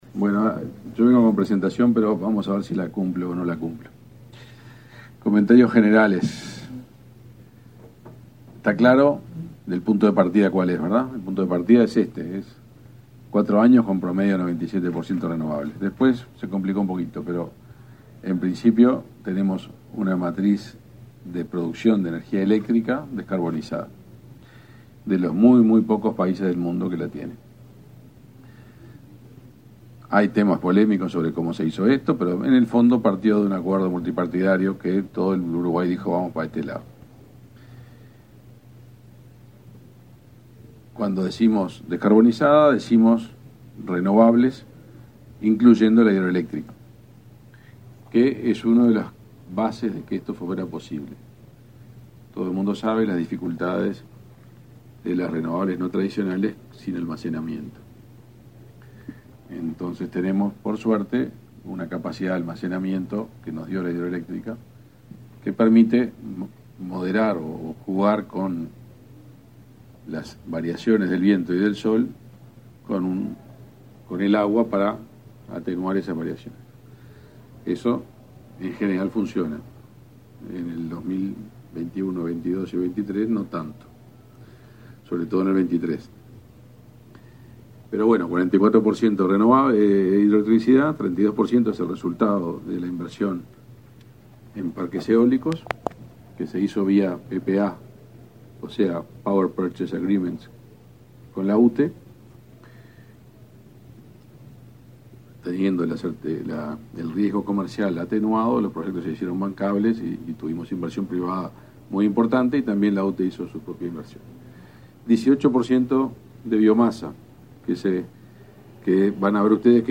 Exposición de autoridades en la Expo Uruguay Sostenible sobre la estrategia del país en la segunda transición energética
conferencia.mp3